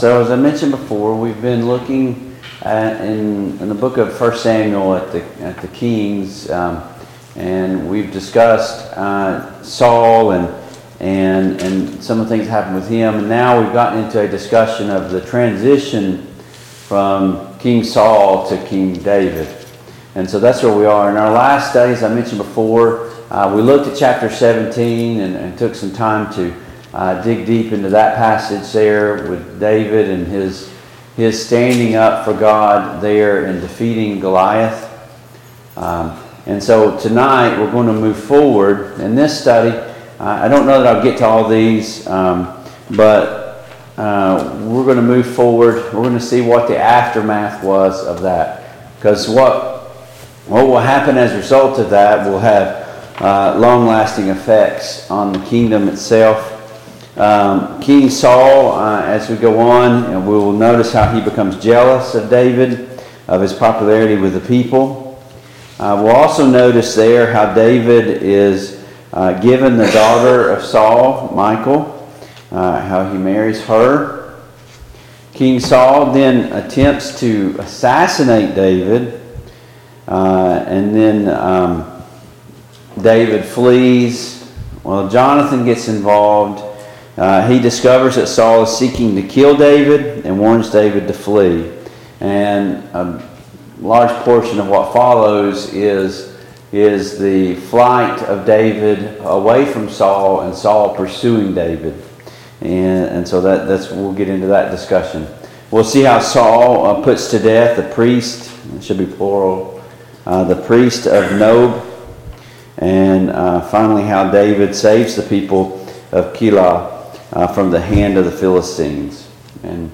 The Kings of Israel Passage: I Samuel 18, I Samuel 19, I Samuel 20 Service Type: Mid-Week Bible Study Download Files Notes « Are we here to Worship or Entertain? 7.